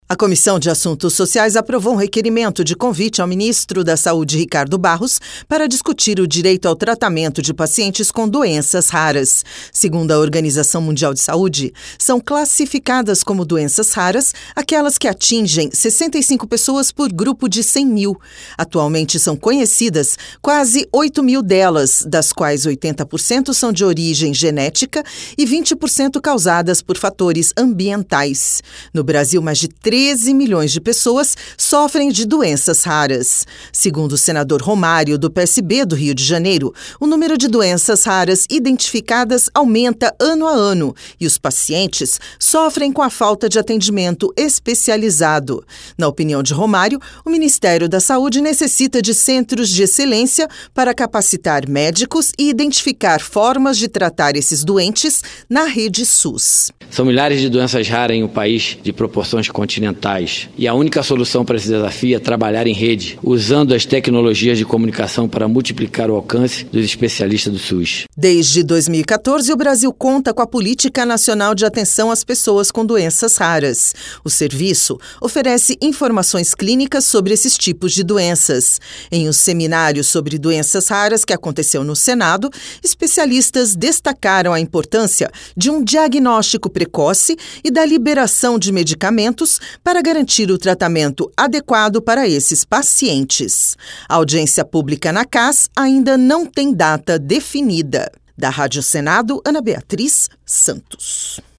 Ouça os detalhes no áudio da repórter